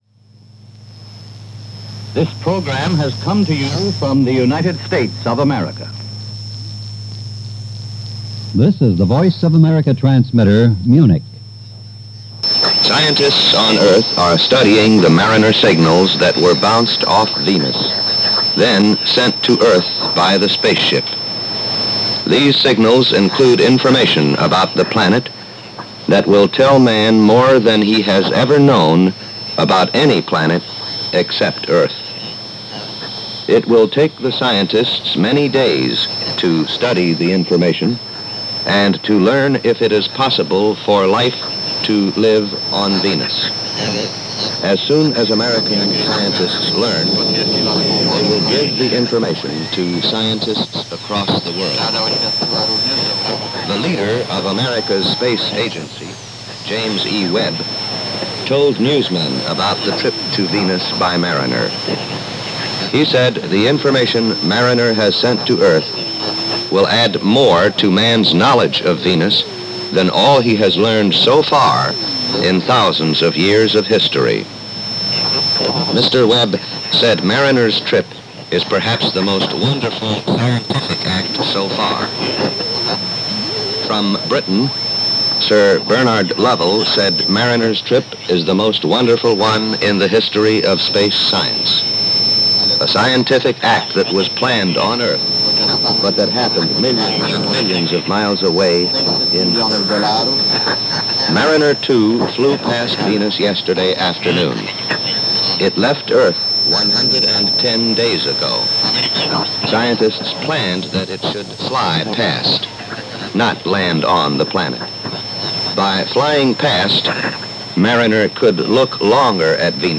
Mariner 2 - Rendezvous With Venus - The Muddle Of Cold War - December 15, 1962 - Voice Of America - shortwave broadcast.